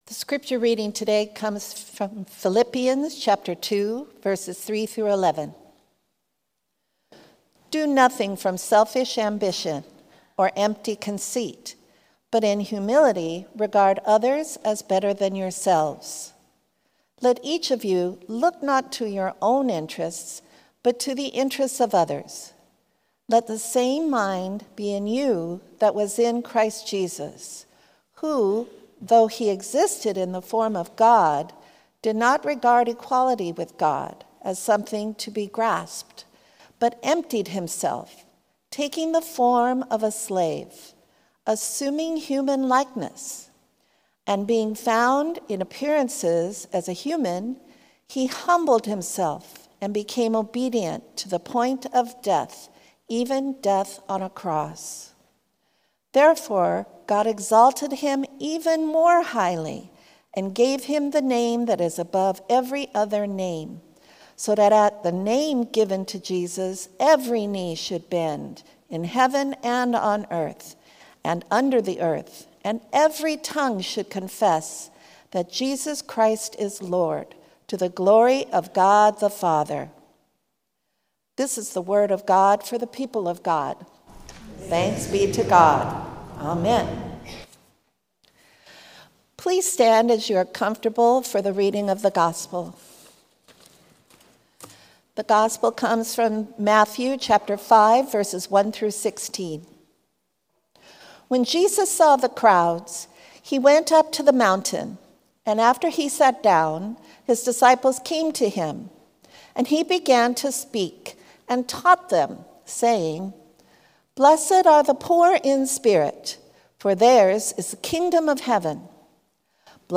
Sermon – Methodist Church Riverside
The-Worlds-Most-Important-Sermon-Part-I.mp3